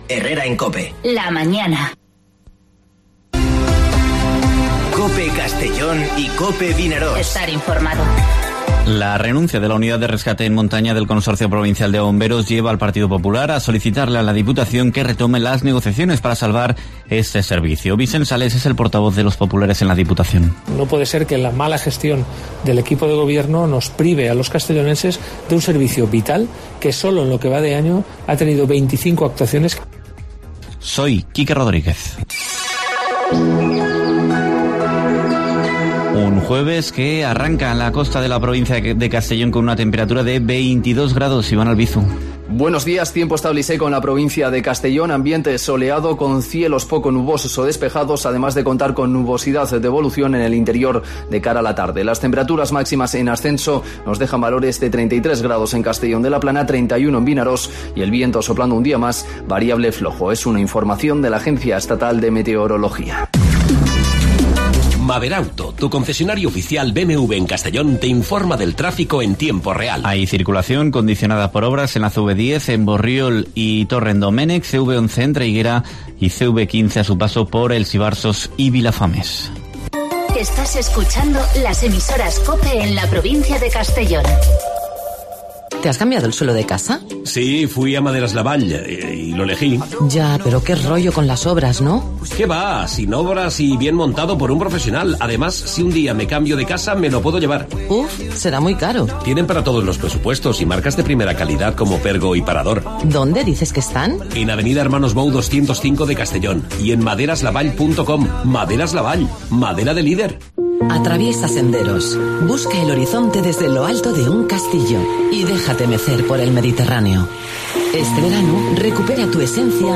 Informativo Herrera en COPE en la provincia de Castellón (23/07/2020)